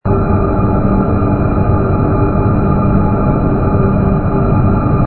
engine_no_loop.wav